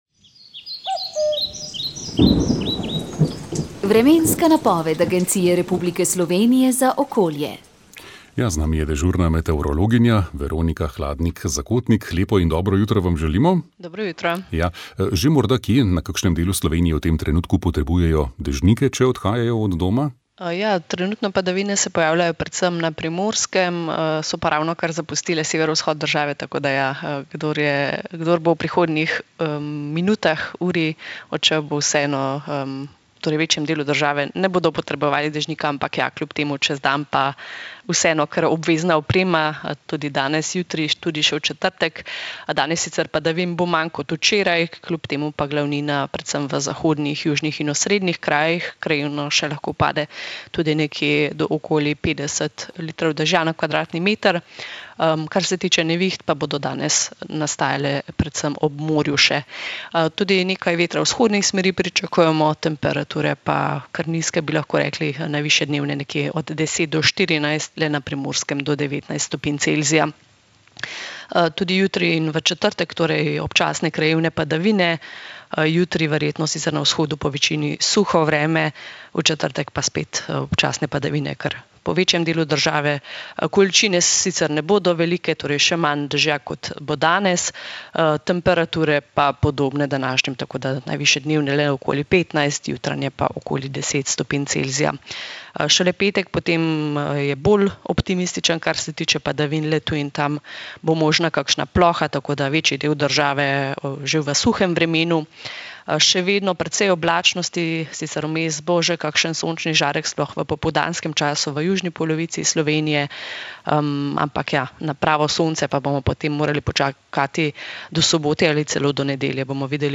Vremenska napoved 05. maj 2025